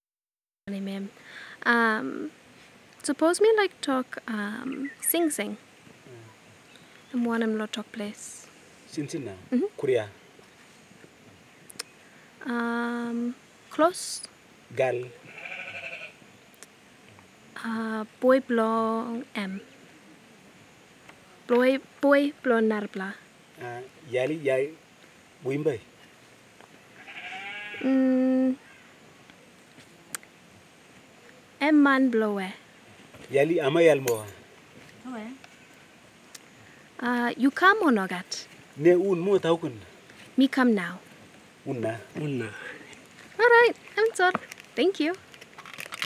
Pitch wordlist with small phrases
digital MP4 file converted from MTS file recorded on Canon XA-20 video recorder
Du, Chimbu Province, Papua New Guinea